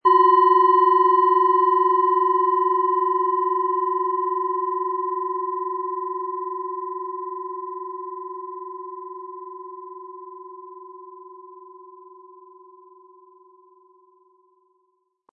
Tibetische Fuss- und Bauch-Klangschale, Ø 10,1 cm, 100-180 Gramm, mit Klöppel
Sie möchten den schönen Klang dieser Schale hören? Spielen Sie bitte den Originalklang im Sound-Player - Jetzt reinhören ab.
Im Preis enthalten ist ein passender Klöppel, der die Töne der Schale schön zum Schwingen bringt.
SchalenformBihar
MaterialBronze